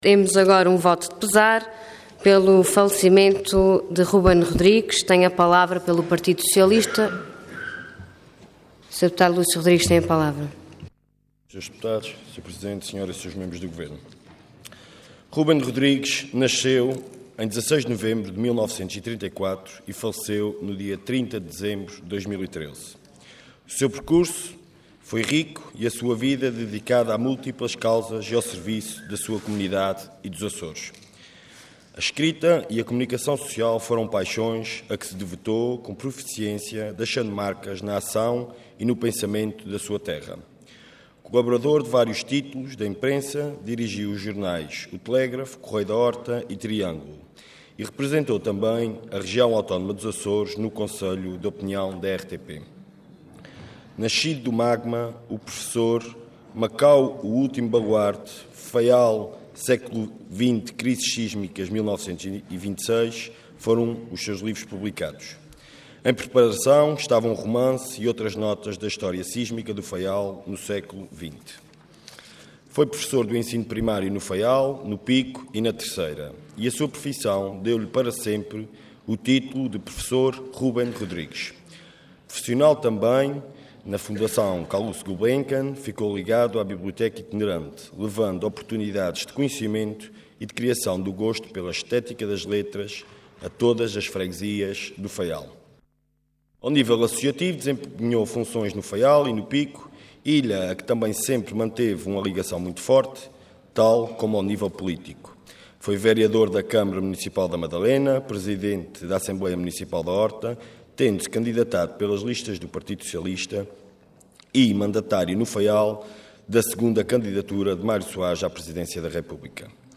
Detalhe de vídeo 16 de janeiro de 2014 Download áudio Download vídeo Processo X Legislatura Falecimento Ruben Rodrigues Intervenção Voto de Pesar Orador Lúcio Rodrigues Cargo Deputado Entidade PS